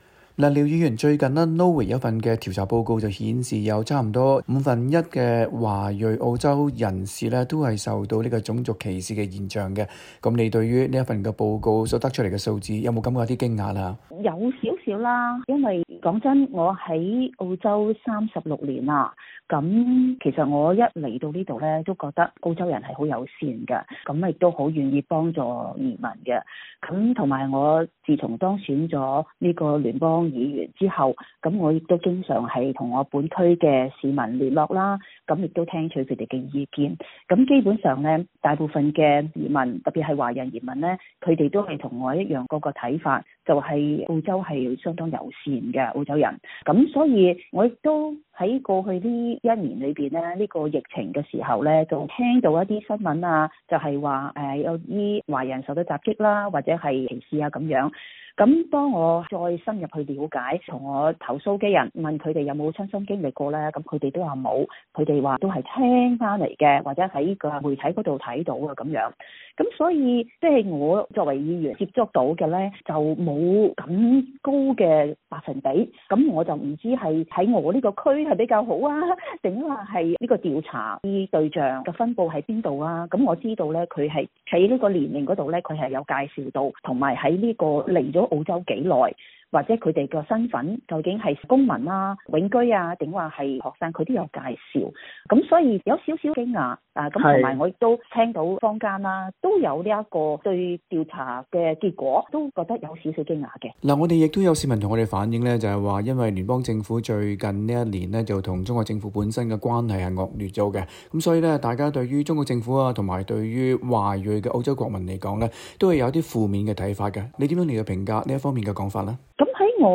mp_gladys_interview_0.mp3